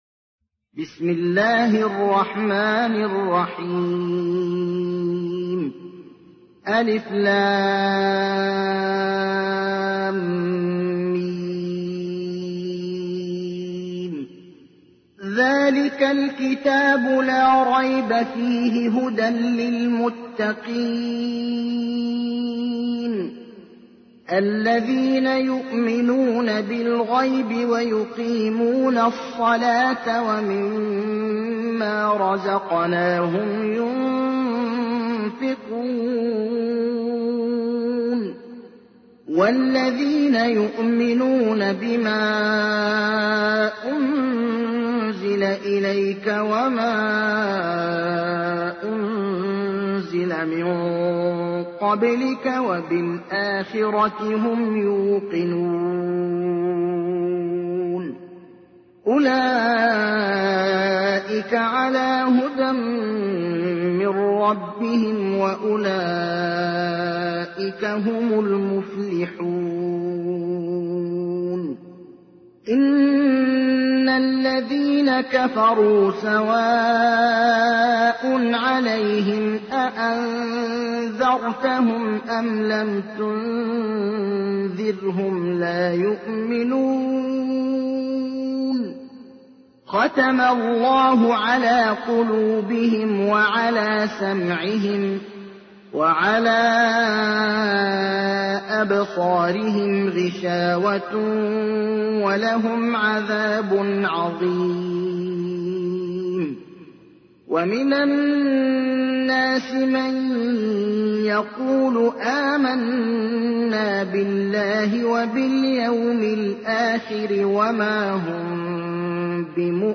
تحميل : 2. سورة البقرة / القارئ ابراهيم الأخضر / القرآن الكريم / موقع يا حسين